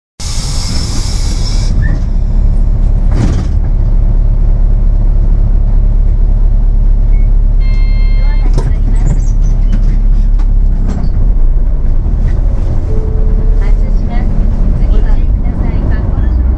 音声合成装置  クラリオン(ディスプレイ１)